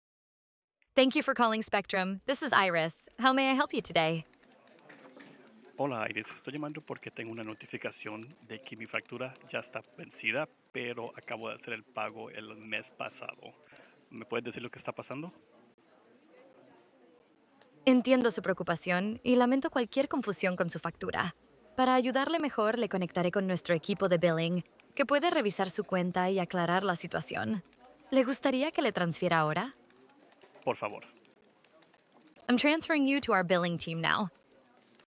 Hear how AI answers real calls
Multilingual request
• Natural, conversational voice